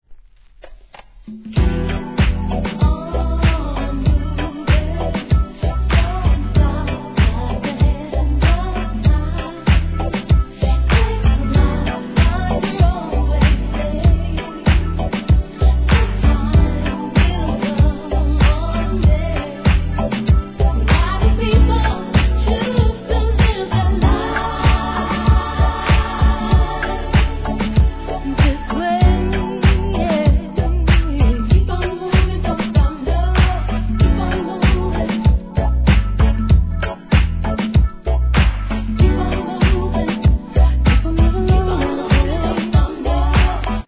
REGGAE
名曲カヴァー♪